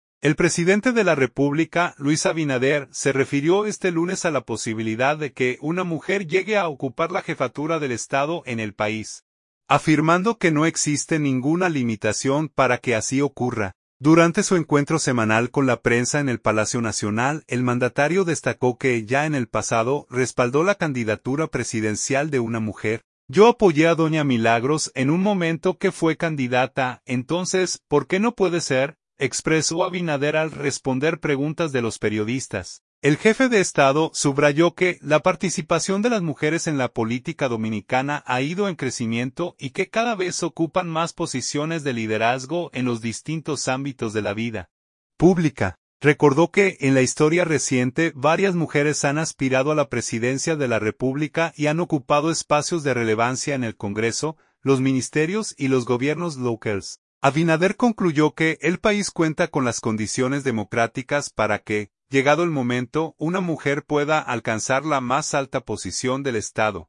Durante su encuentro semanal con la prensa en el Palacio Nacional, el mandatario destacó que ya en el pasado respaldó la candidatura presidencial de una mujer.
"Yo apoyé a doña Milagros en un momento que fue candidata, entonces, ¿por qué no puede ser?", expresó Abinader al responder preguntas de los periodistas.